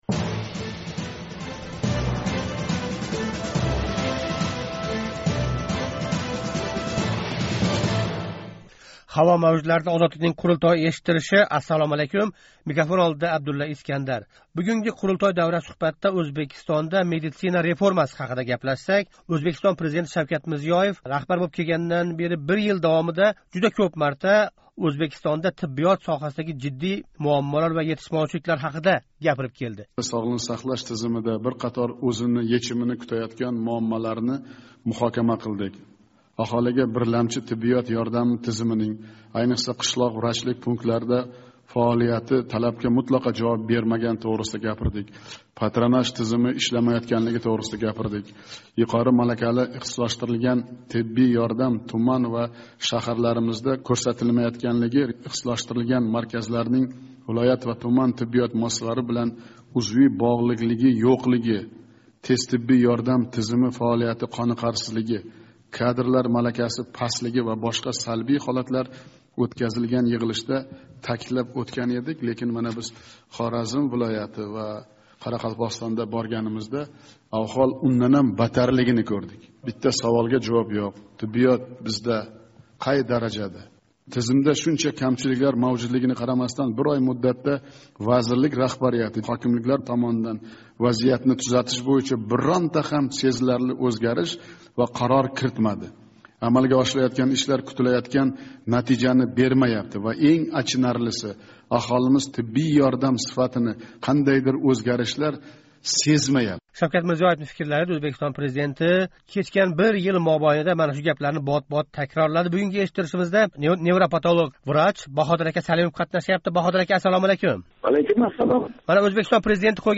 Бу савол жавобини Озодликнинг қурултой дастурида изладик. Эшиттиришда Ўзбекистон ва дунëнинг етакчи клиникалрида ишлаëтган ўзбек врачлари иштирок этди.